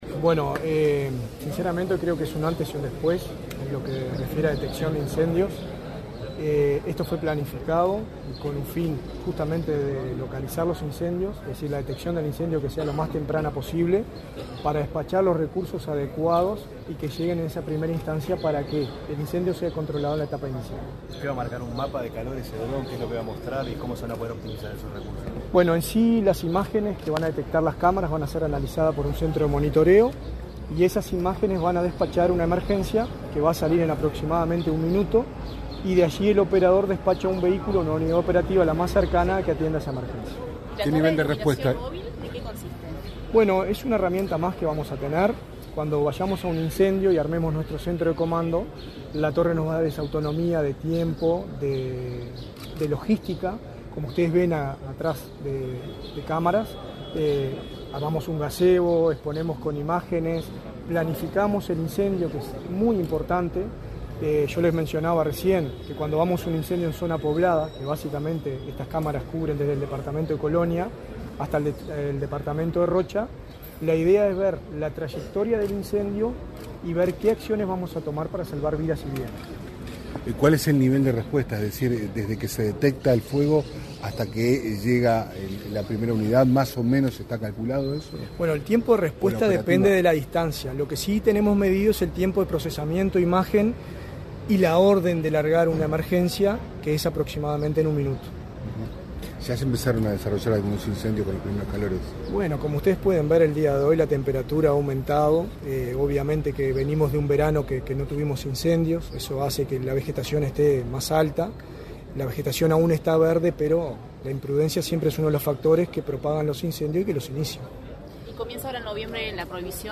Declaraciones a la prensa del director de Bomberos, Richard Barboza
Declaraciones a la prensa del director de Bomberos, Richard Barboza 21/10/2024 Compartir Facebook X Copiar enlace WhatsApp LinkedIn Con la presencia del ministro del Interior, Nicolás Martinelli, este 21 de octubre, fueron presentadas las cámaras de videovigilancia y otro equipamiento destinado a la Dirección Nacional de Bomberos. Tras el evento, el titular de la dependencia, Richard Barboza, realizó declaraciones a la prensa.